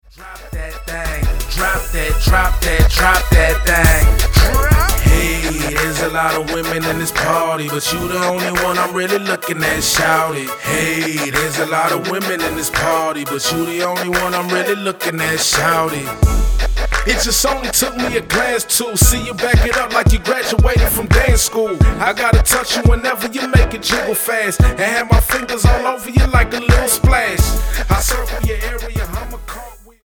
NOTE: Vocal Tracks 1 Thru 9